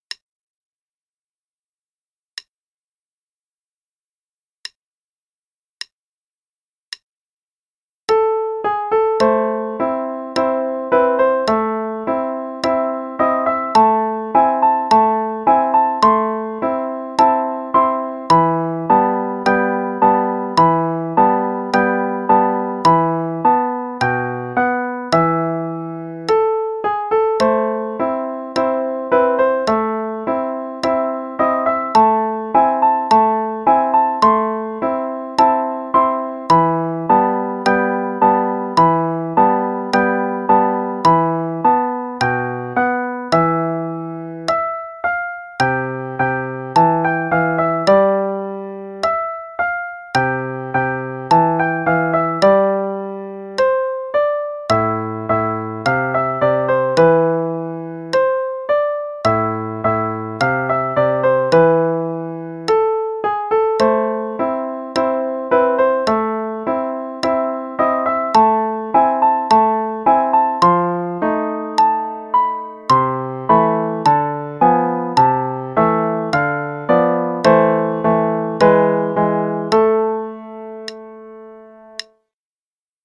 Rondo alla Turca (both hands, qn=54)
Play-along_Mozart - Rondo alla Turca (qn=54).mp3